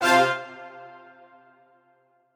admin-leaf-alice-in-misanthrope/strings34_1_013.ogg at a8990f1ad740036f9d250f3aceaad8c816b20b54